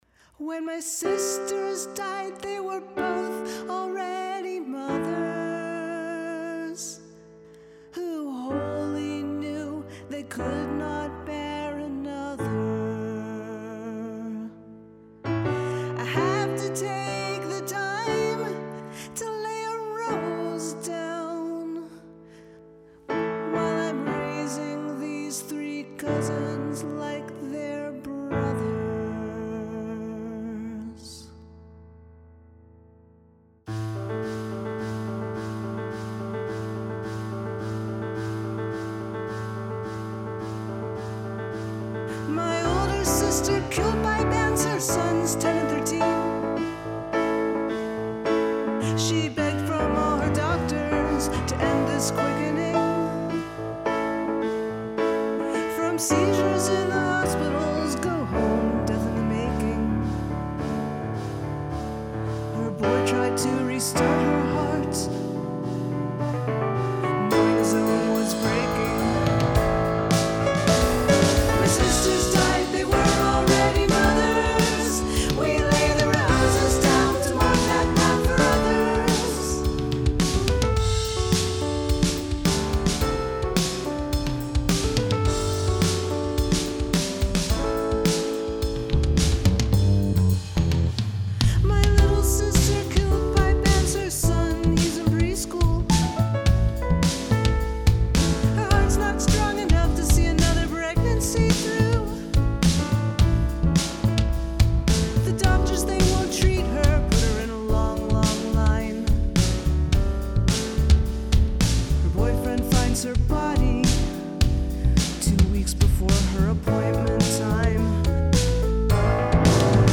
The percussion at the edges is like millions of feet driven to slaughter from these immoral bans and it's utterly gutting.
It was an amazing choice to go with such a wide-ranging melody rather than hiding in 3 chords.
Nice dynamic changes and I love how the vocals build in each chorus.
Some tasty bass playing.. the whole music is really well done. I really like the change in tempo around 2:45...
Wow killer keys!
Love the vocal harmonies.
The remnant of the click track at the end of the song off in the distance makes it really — eerie is not the word, maybe portentous.
There is a sense of urgency in the music that makes one sit up and listen.